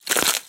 На этой странице собрана коллекция звуков кулаков — резкие удары, глухие толчки, звонкие столкновения.
Звук сжатия предмета в кулаке и его хруст